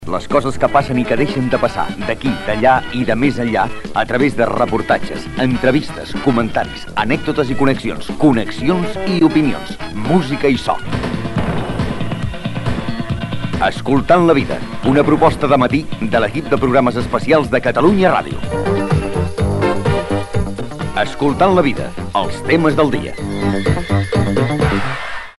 Careta d'entrada del programa
FM